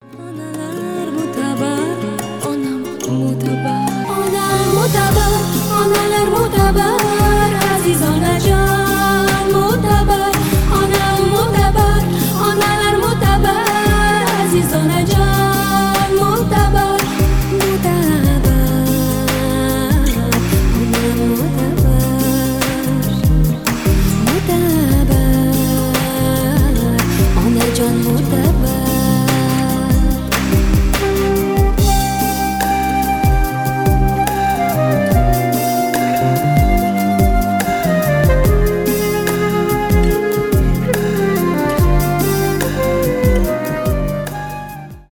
женский голос , поп , узбекские